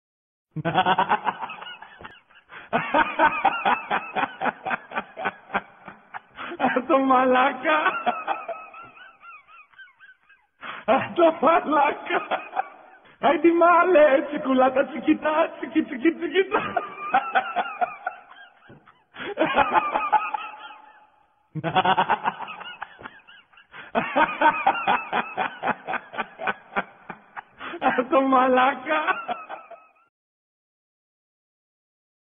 Risada Atumalaca original
Categoria: Risadas
risada-atumalaca-original-pt-www_tiengdong_com.mp3